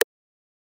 button_click.mp3